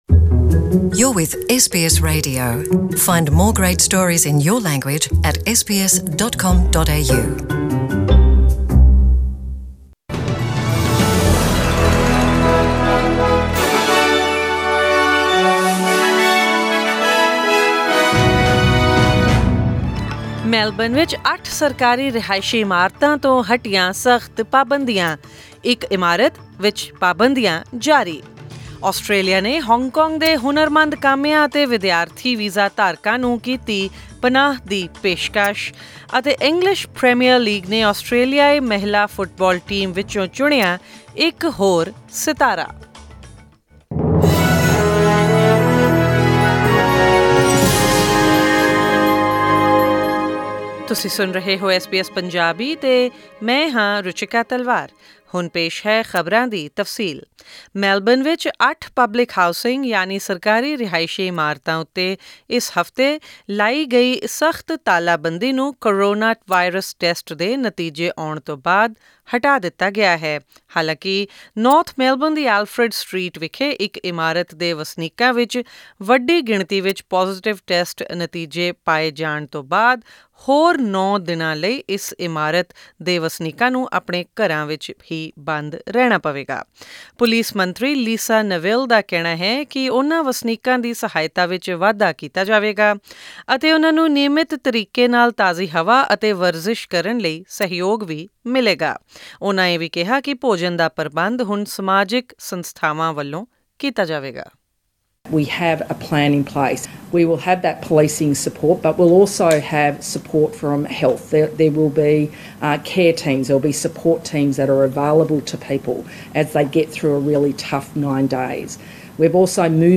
Australian News in Punjabi: 9 July 2020